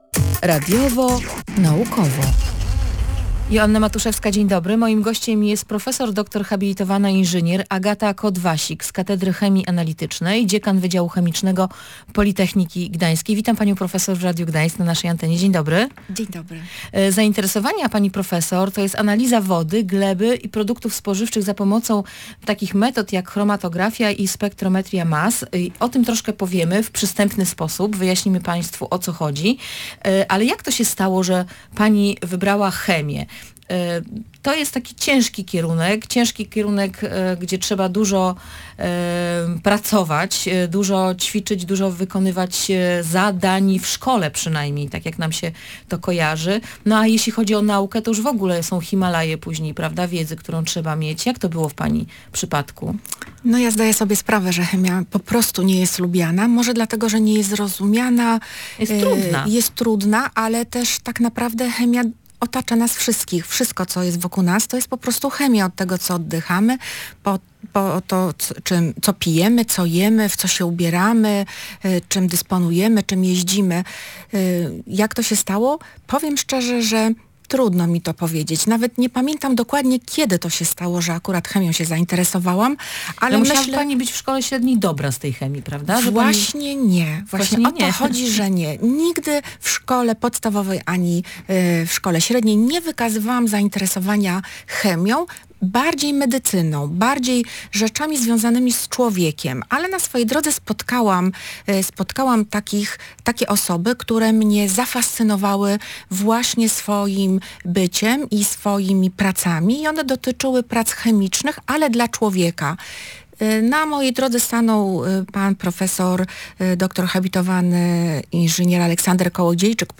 rozmawiała z nią w audycji „Radiowo-Naukowo”